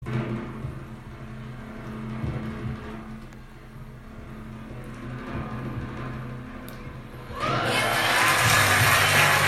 Drumroll final decision